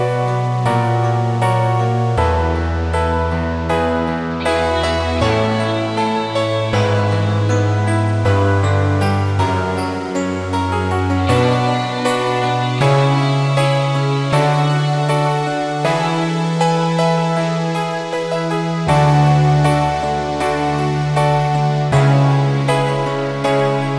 No Piano With Backing Vocals Pop (1980s) 4:45 Buy £1.50